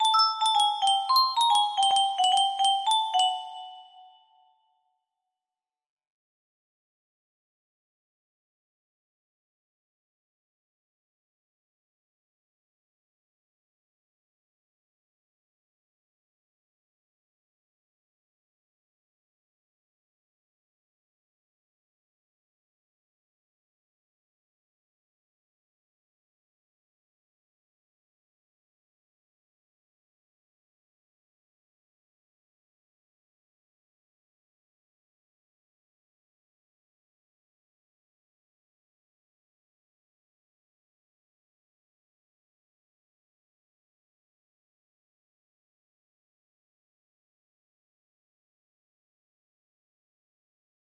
Hitzuna lol music box melody